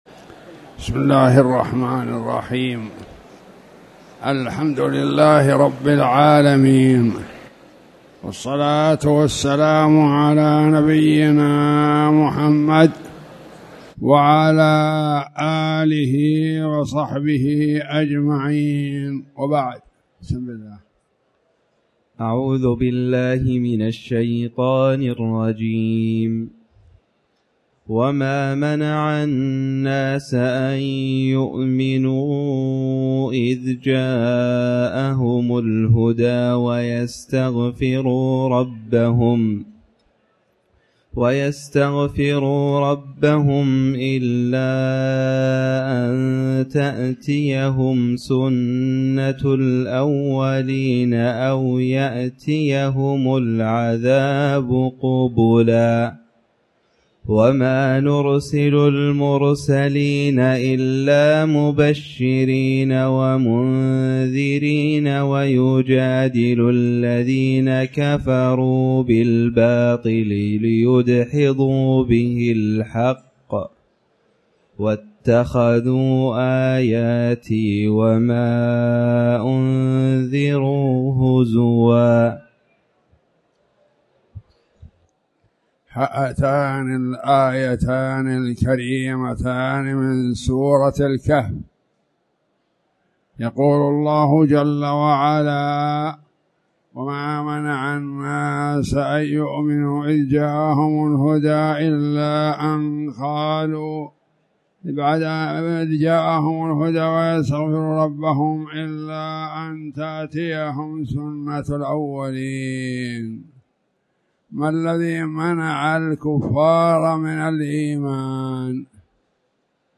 تاريخ النشر ٢٢ ذو الحجة ١٤٣٨ هـ المكان: المسجد الحرام الشيخ